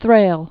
(thrāl), Mrs.